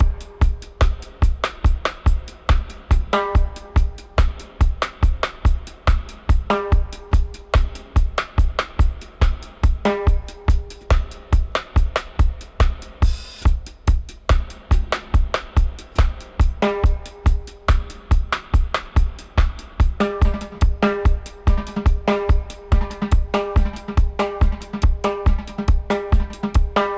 142-FX-04.wav